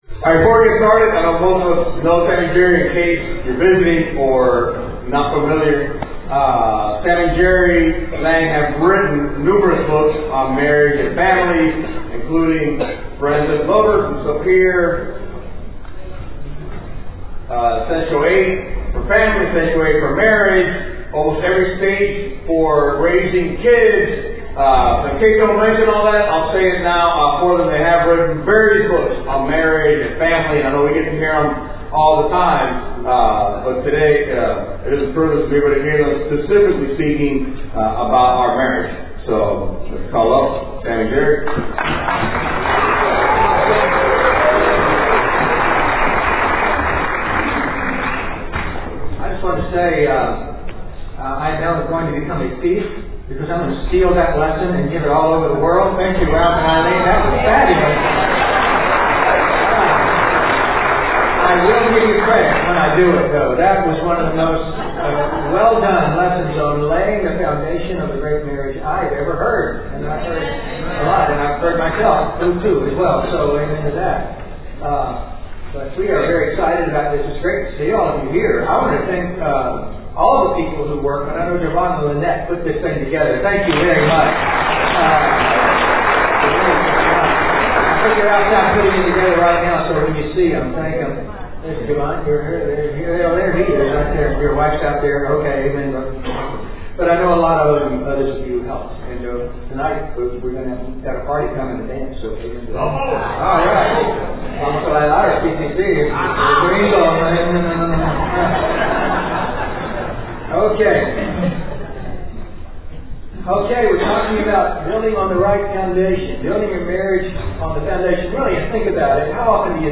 2016 Marriage Retreat - Remodel Your Marriage From the Ground Up was hosted by the Palm Beach Church.